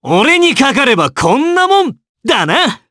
Gladi-Vox_Victory_jp_b.wav